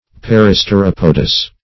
Search Result for " peristeropodous" : The Collaborative International Dictionary of English v.0.48: Peristeropodous \Pe*ris`ter*op"o*dous\, a. [Gr. peristera` a pigeon + poy`s, podo`s, foot.]
peristeropodous.mp3